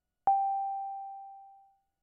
Roland Juno 6 Sine pluck " Roland Juno 6 Sine pluck F4 ( Sine pluck67127)
标签： FSharp4 MIDI音符-67 罗兰朱诺-6 合成器 单票据 多重采样
声道立体声